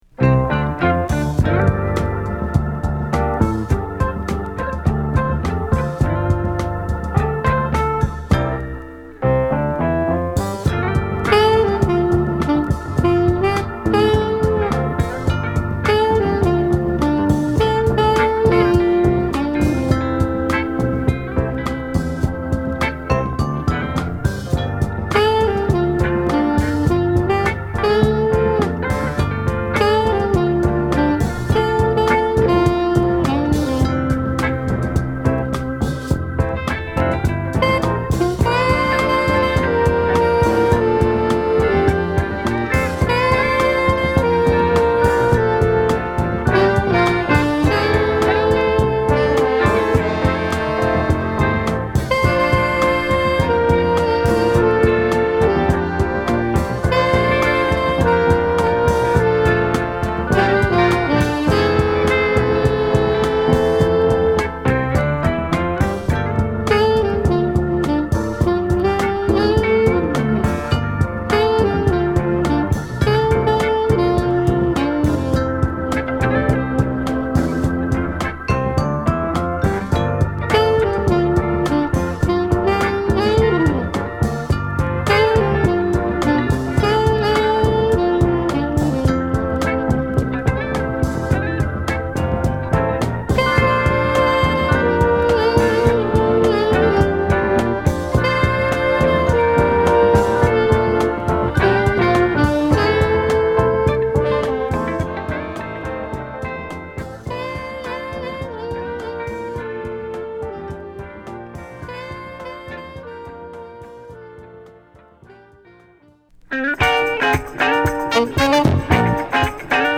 ファンキーなソウル・ジャズに寄った作品で